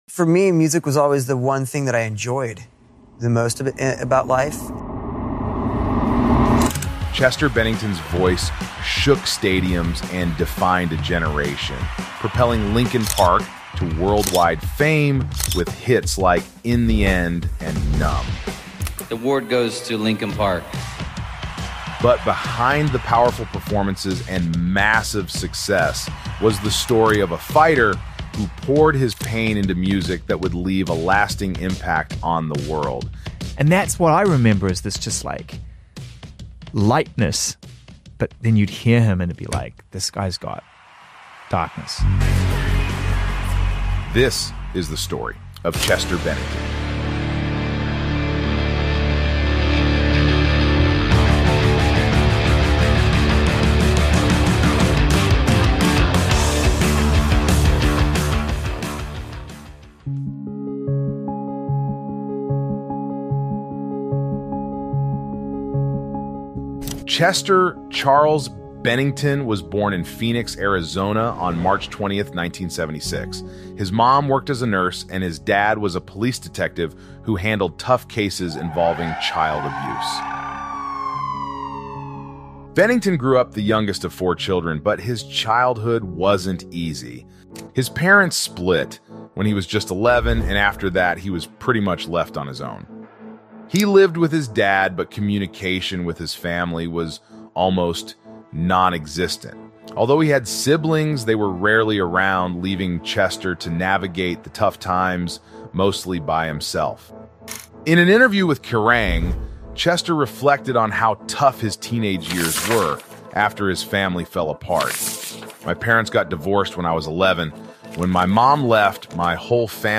The TRAGIC Story of Linkin Park’s Chester Bennington(Documentary)